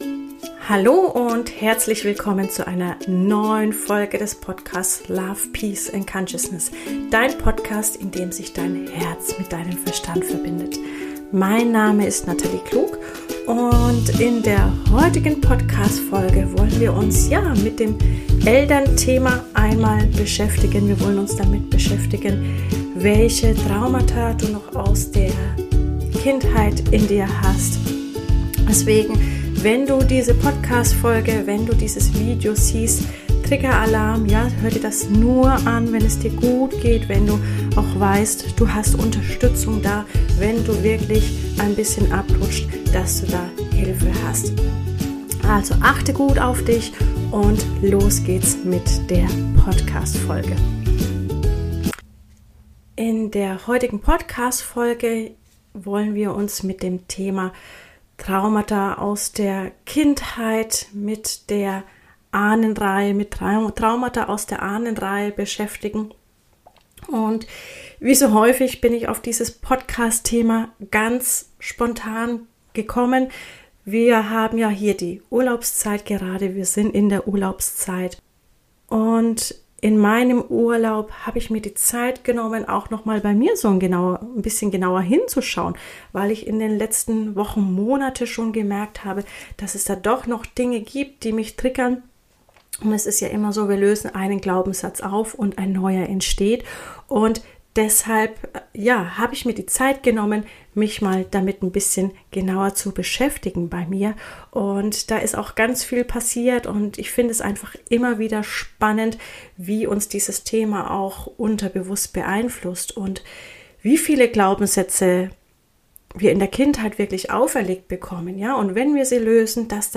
Eine geführte Meditation hilft dir, die Verbindung zu deinem Herzen  und deinen Ahnen zu stärken, um mehr Liebe und Freiheit in dein Leben zu bringen.